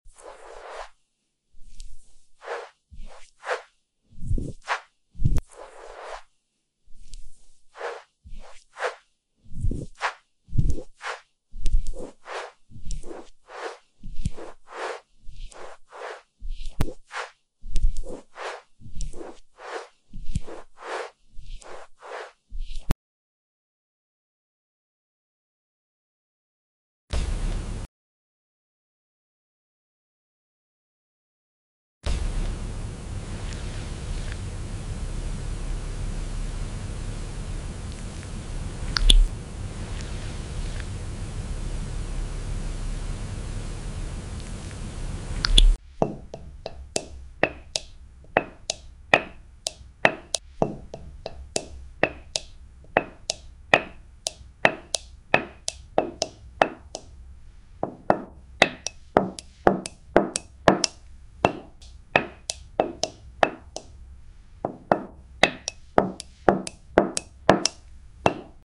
Hydration meets relaxation ✨ Watch glowing glass skin, serum drops, and skincare sounds combine in this soothing ASMR routine. Perfect for sleep, calm, and skincare lovers.